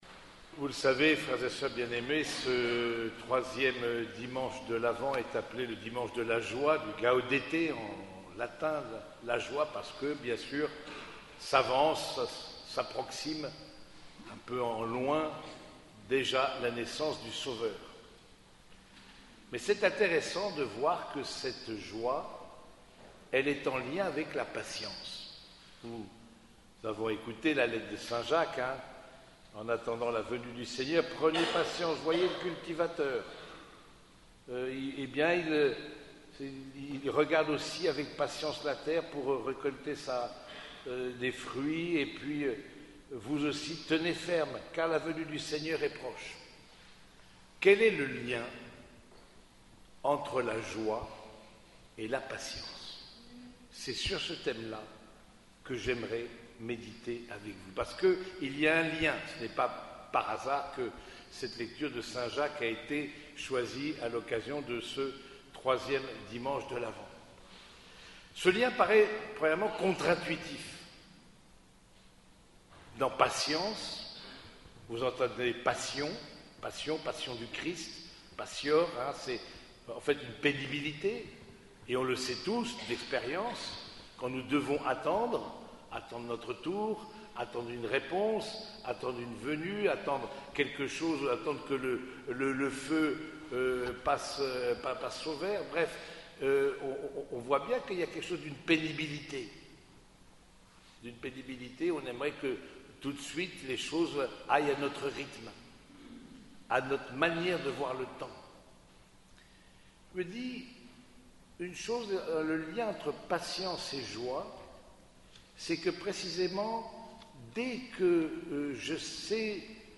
Homélie du quatrième dimanche de Pâques